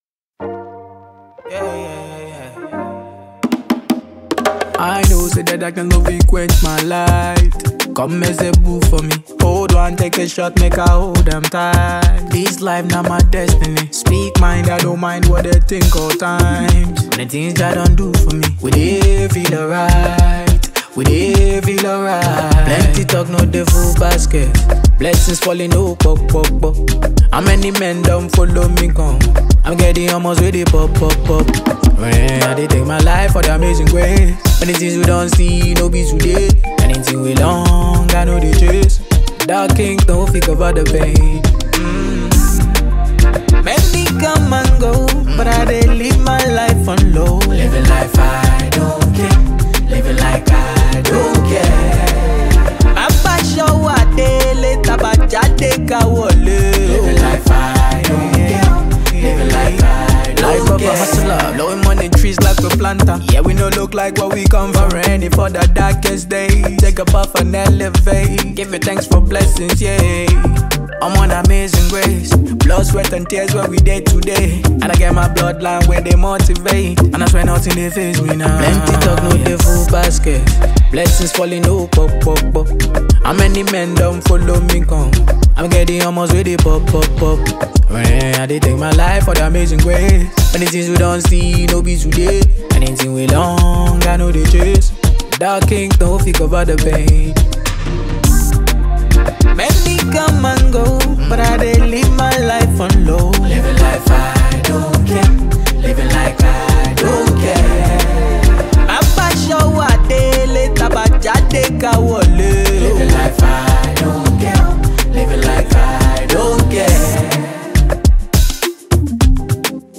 Grammy Award winning Nigerian heavyweight Afrobeat Singer
studio Album